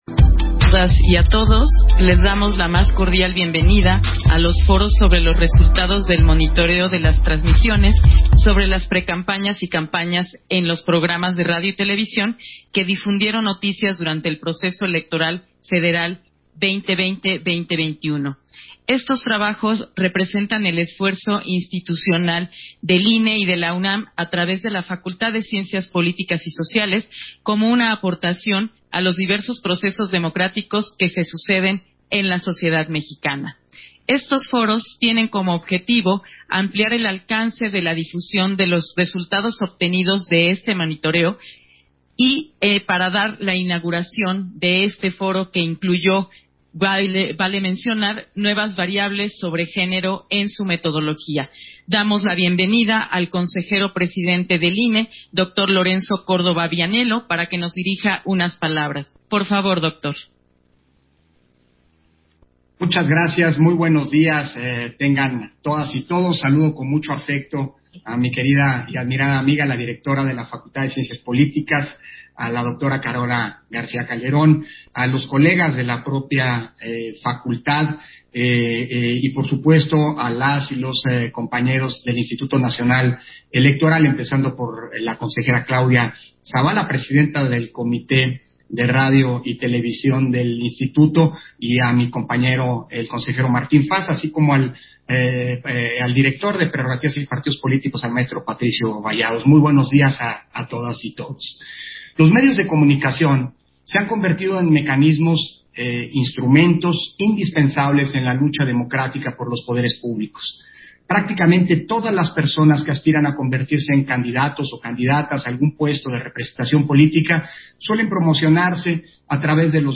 170821_AUDIO_INAUGURACIÓN-DEL-CICLO-DE-FOROS-SOBRE-LOS-RESULTADOS-DEL-MONITOREO